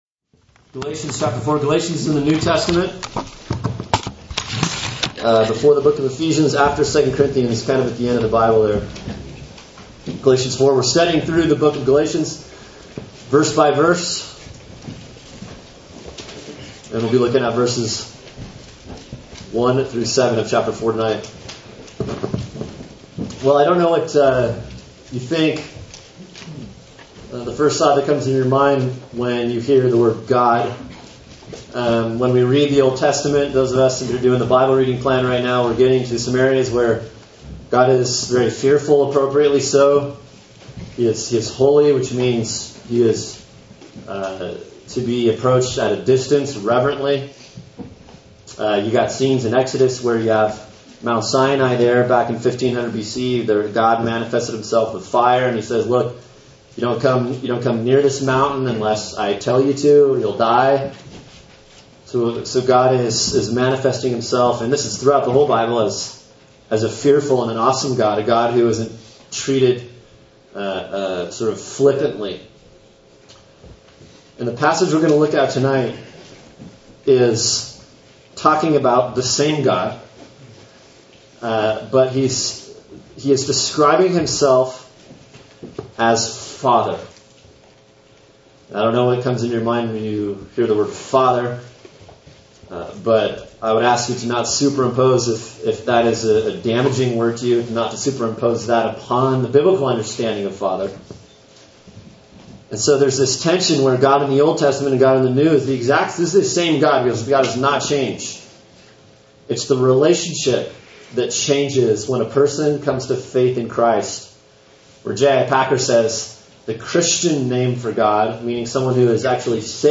Sermon: Galatians 4:1-7 “From Slaves To Sons” | Cornerstone Church - Jackson Hole